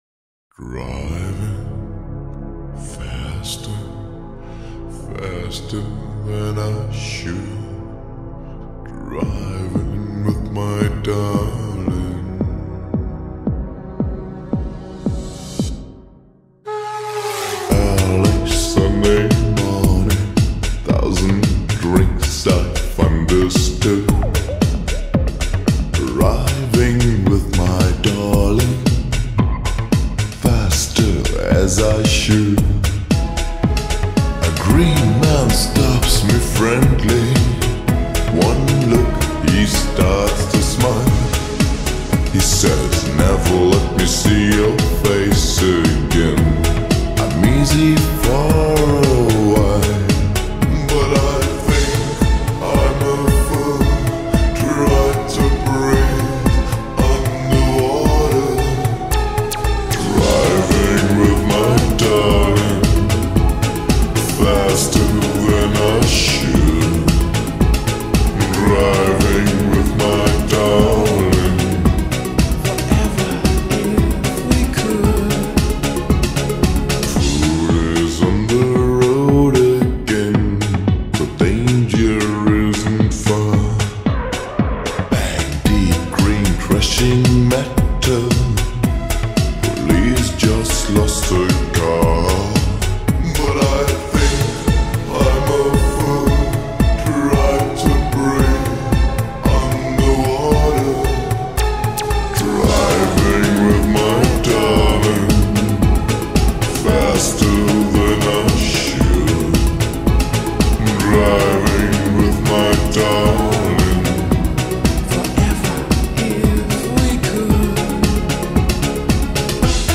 با ریتمی کند شده